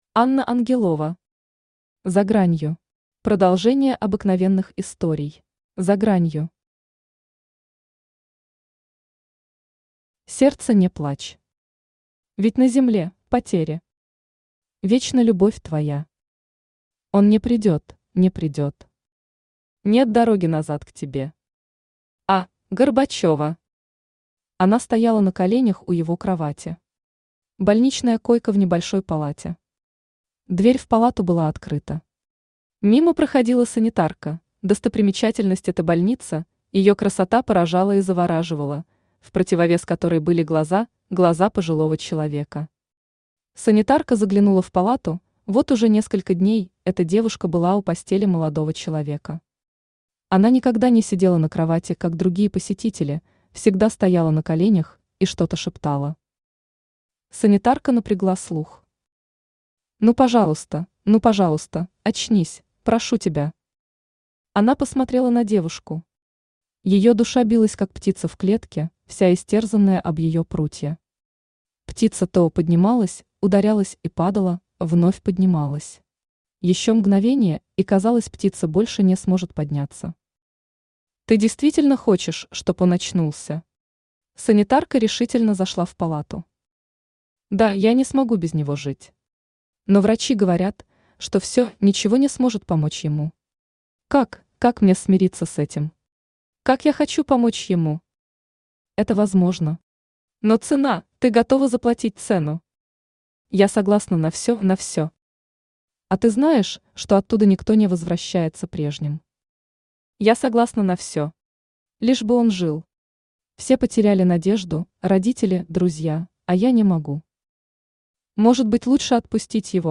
Аудиокнига За гранью. Продолжение обыкновенных историй | Библиотека аудиокниг
Продолжение обыкновенных историй Автор Анна Ангелова Читает аудиокнигу Авточтец ЛитРес.